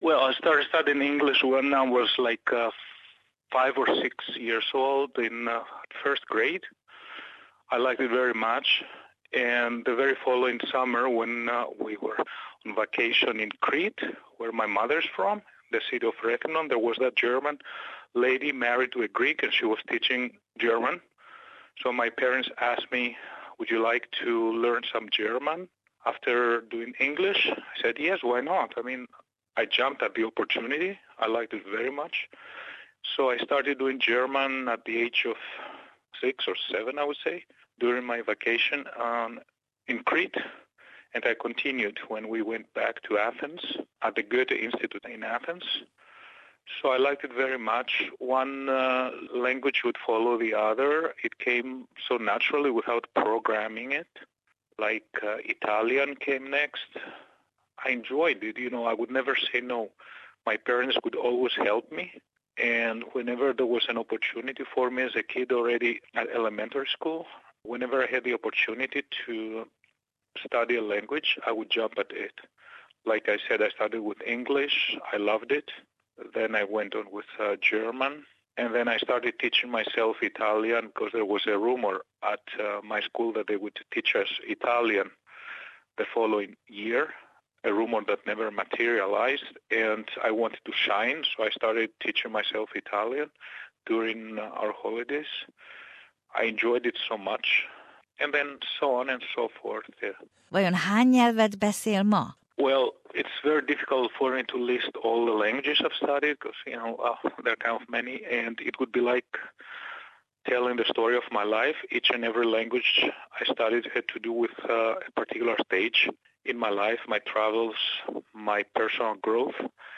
SBS Hungarian